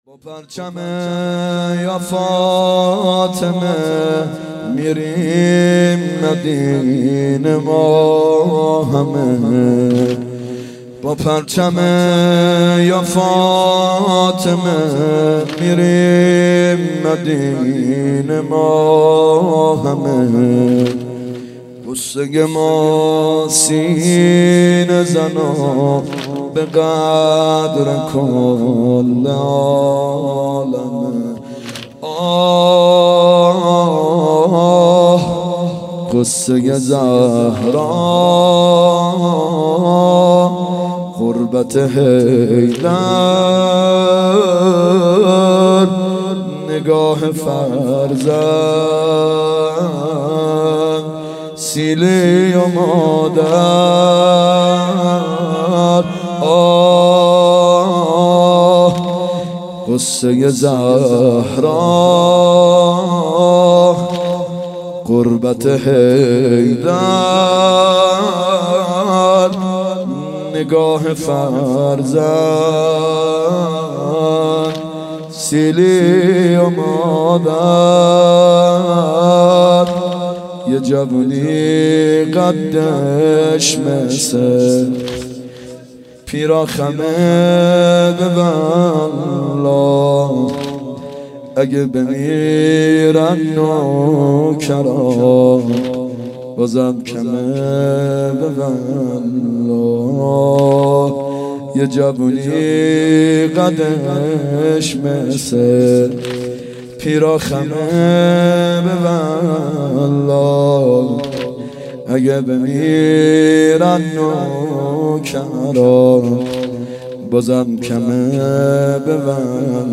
فاطمیه دوم هیئت یامهدی (عج)